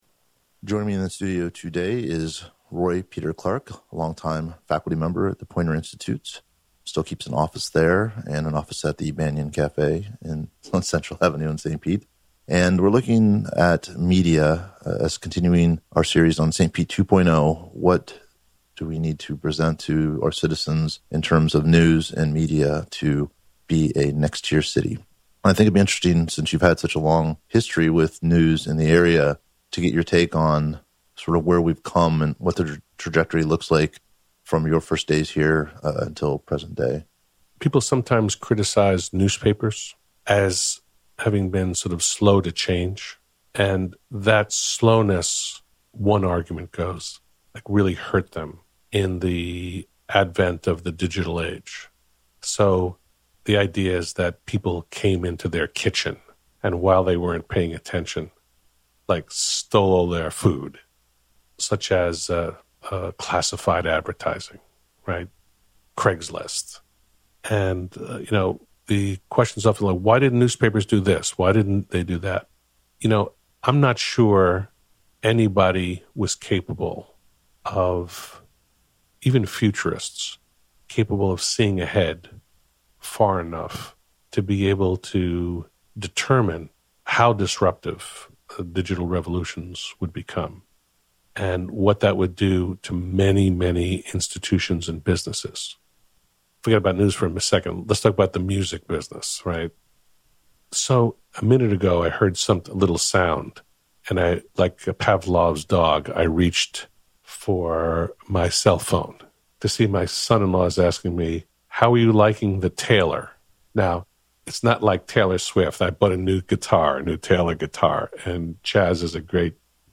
Author Roy Peter Clark joins the St. Pete 2.0 conversation as we examine news in St. Petersburg.